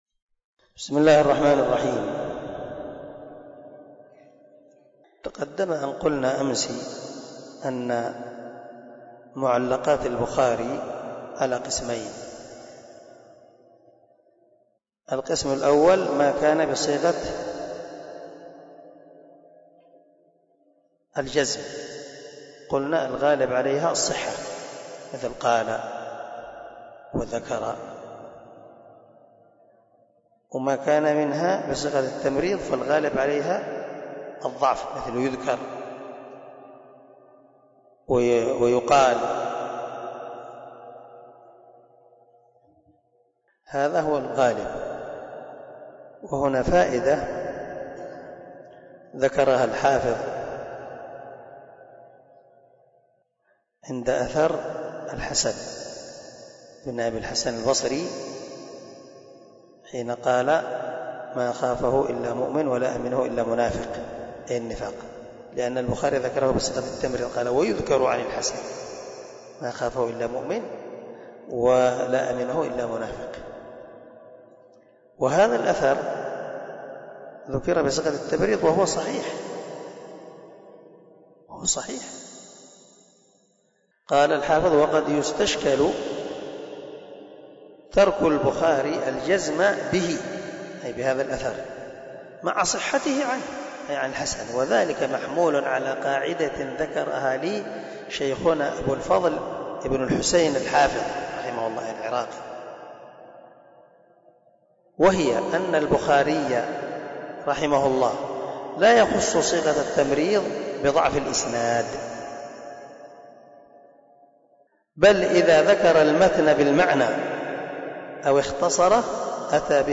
048الدرس 38 من شرح كتاب الإيمان حديث رقم ( 49 ) من صحيح البخاري